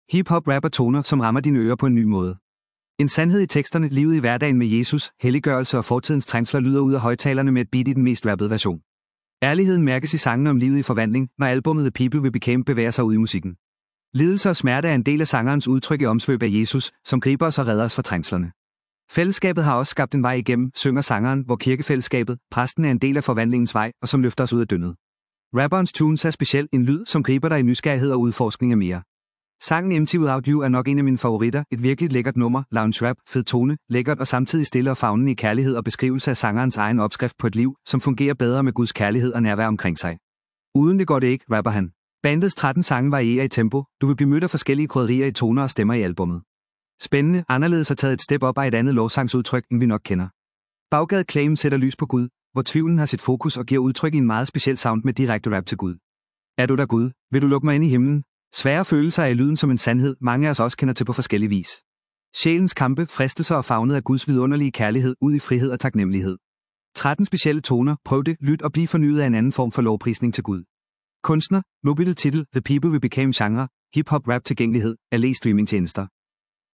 Genre: Hip Hop Rap